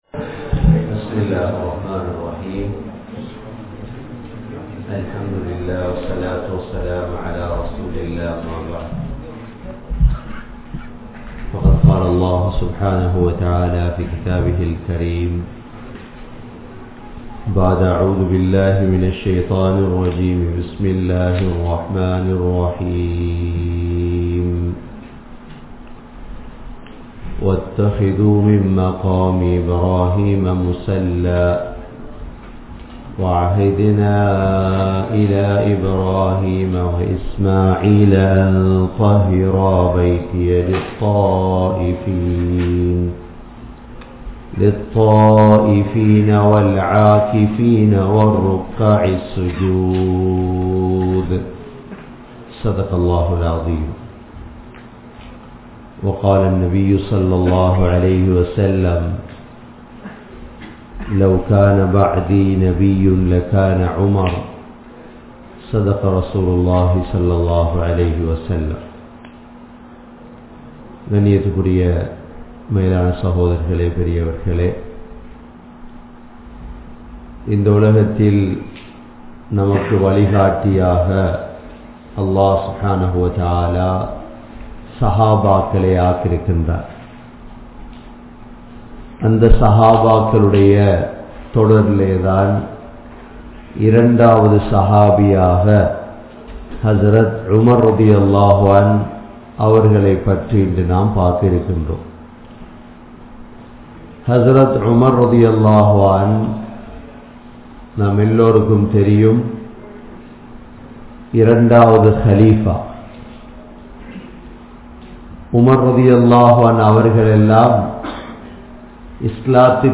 Umar(Rali) | Audio Bayans | All Ceylon Muslim Youth Community | Addalaichenai